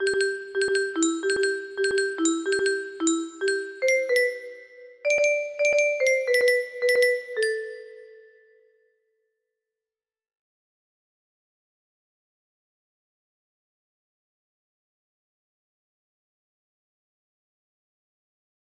X max music box melody